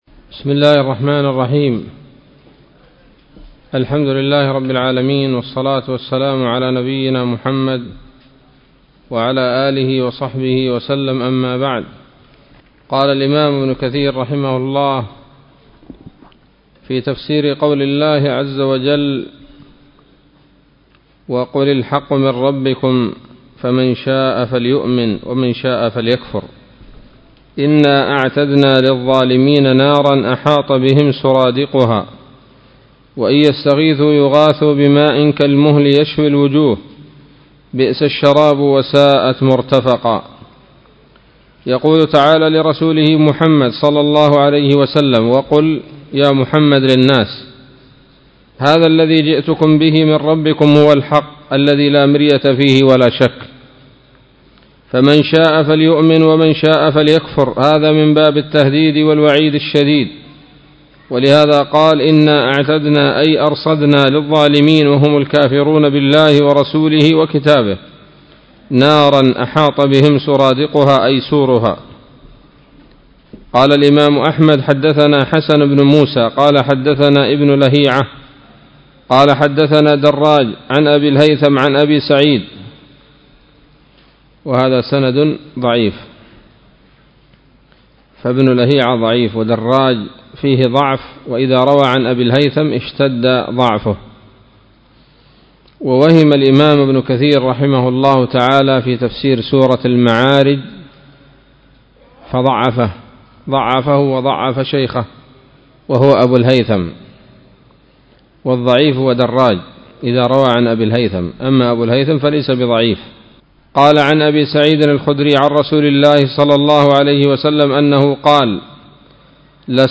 الدرس الثامن من سورة الكهف من تفسير ابن كثير رحمه الله تعالى